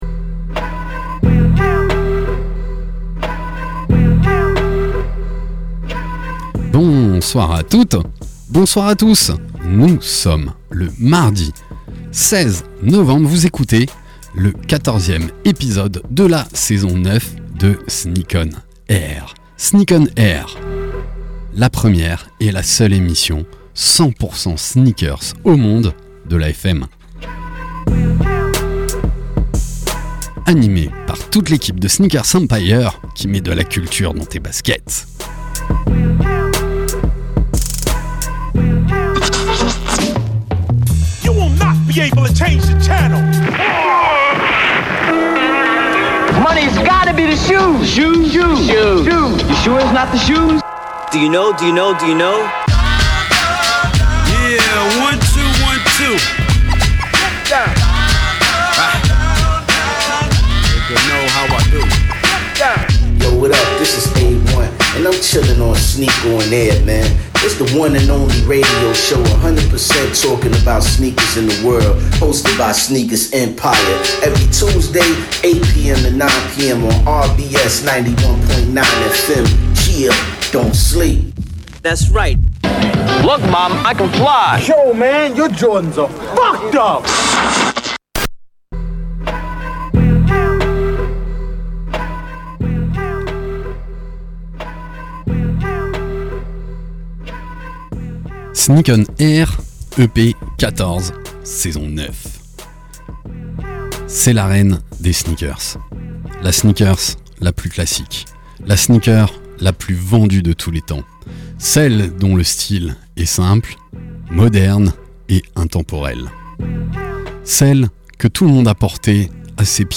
Sneak ON AIR, la première et la seule émission de radio 100% sneakers au monde !!! sur la radio RBS tous les mardis de 20h à 21h. Animée par l’équipe de Sneakers EMPIRE. Actu sneakers, invités, SANA, talk.